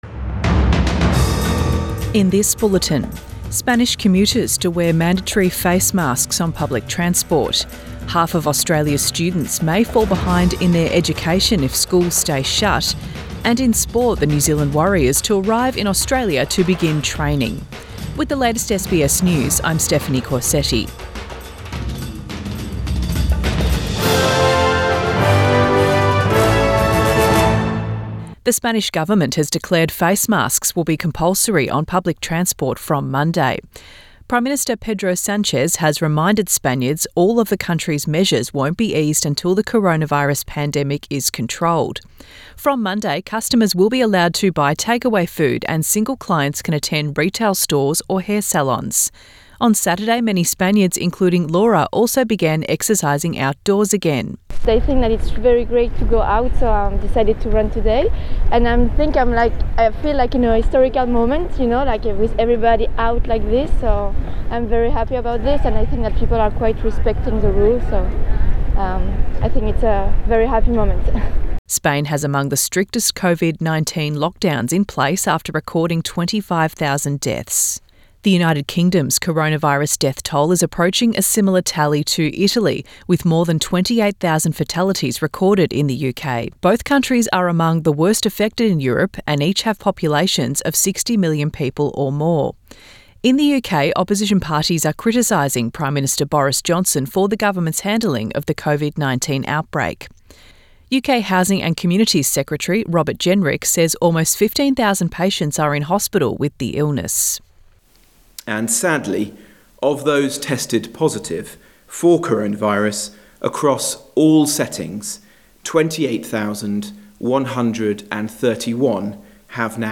AM bulletin 3 May 2020